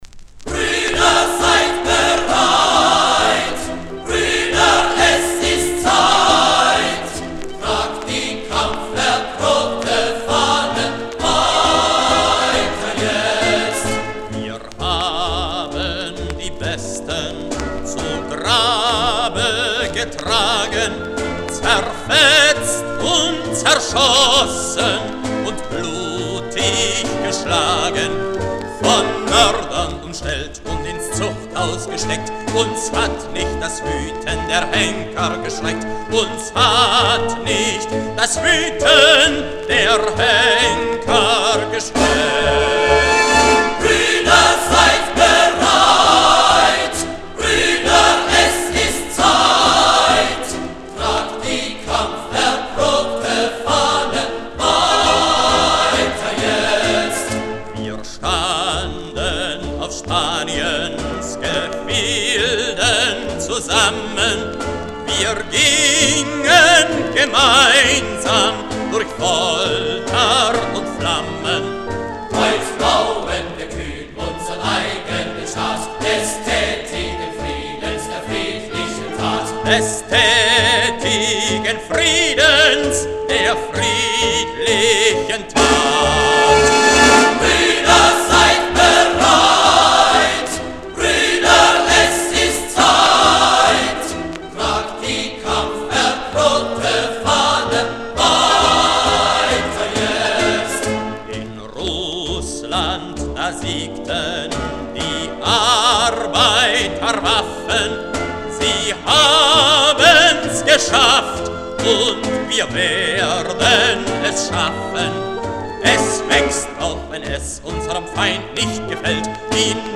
Редкая запись с пластинки